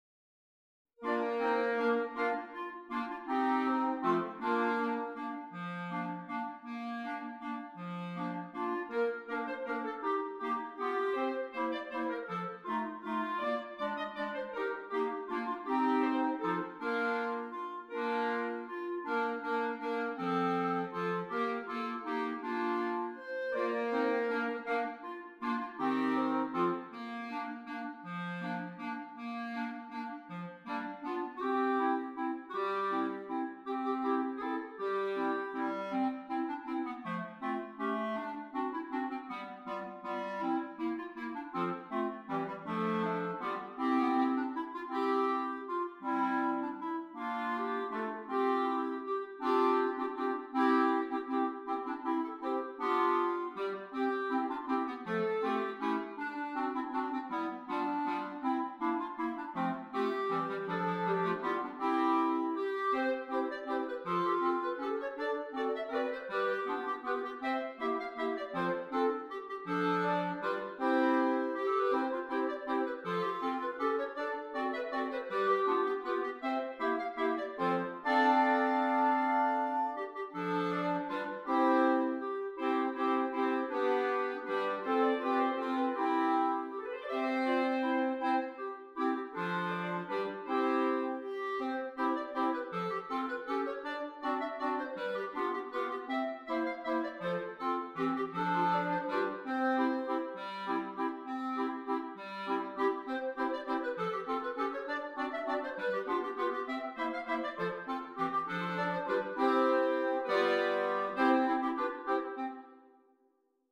6 Clarinets
Traditional Carol